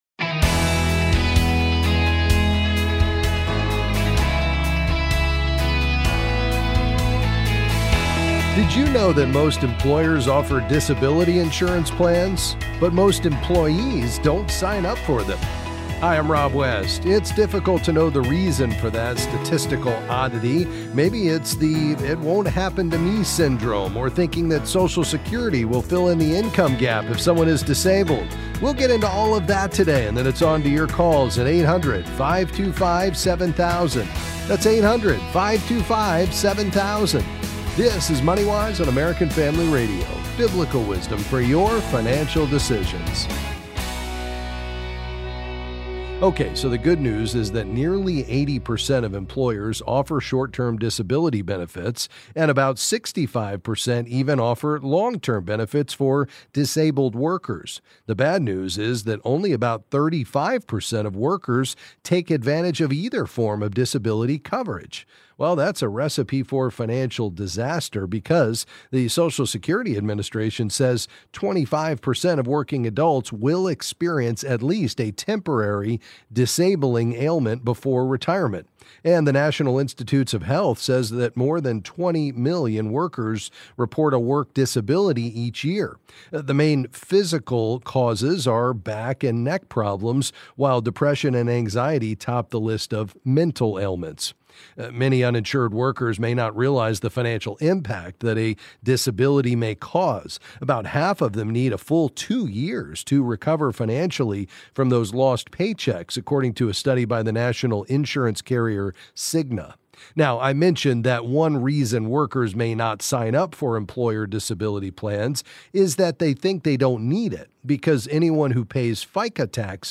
Then he’ll answer your calls on various financial topics.